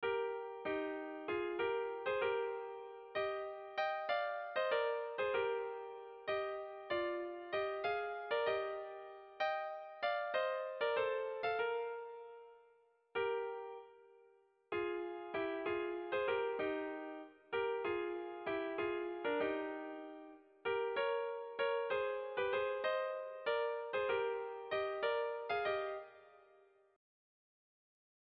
Dantzakoa
Zortziko txikia (hg) / Lau puntuko txikia (ip)